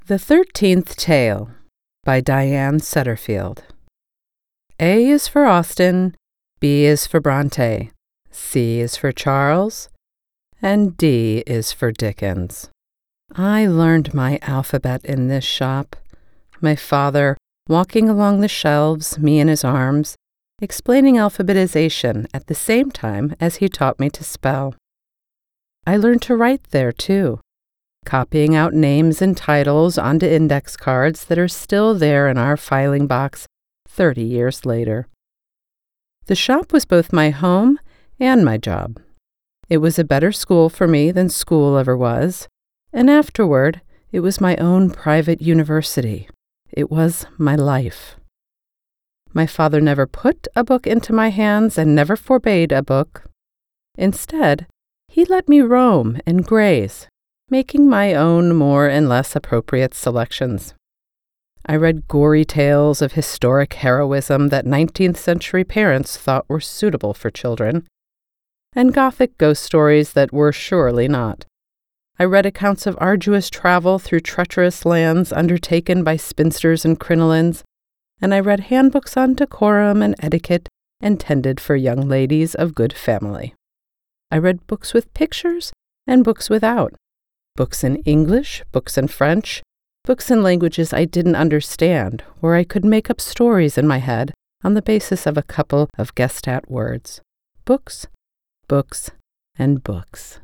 Broadcast Quality Voiceover Talent and Certified Audio Engineer
Gothic Fiction
Working from my broadcast-quality home studio is not just my profession—it’s my joy.